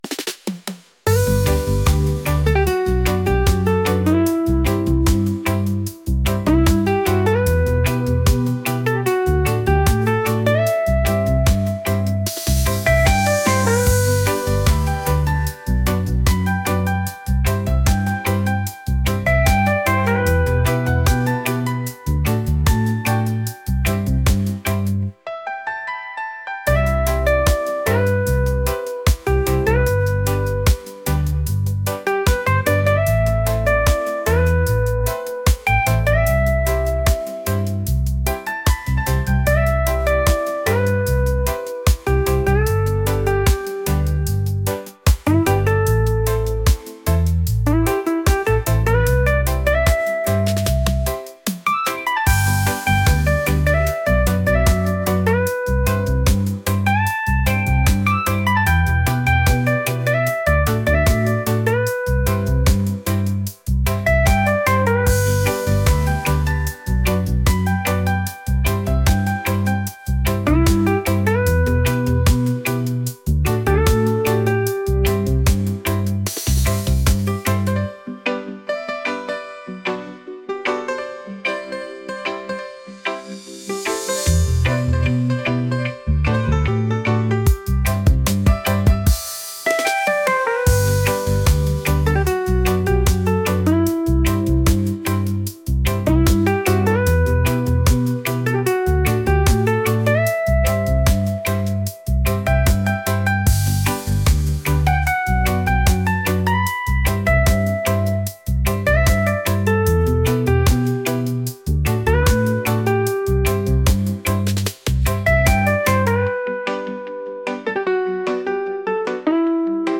romantic | reggae